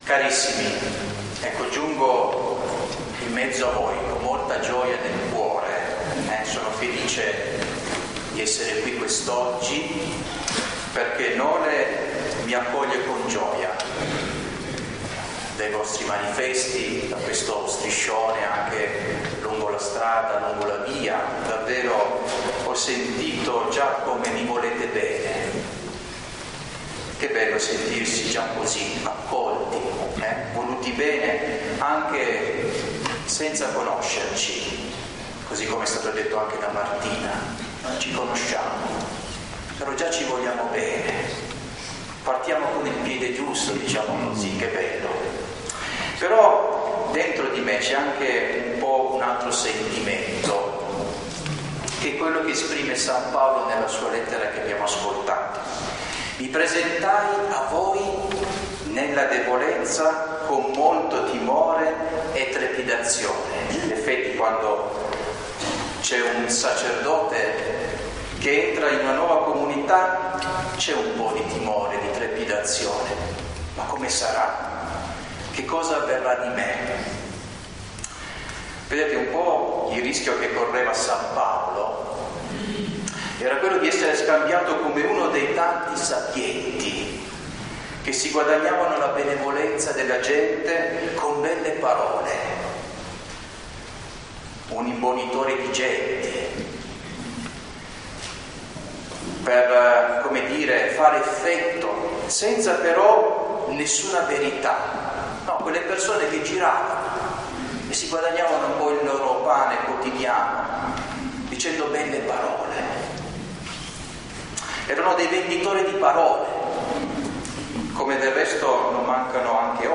Ingresso del parroco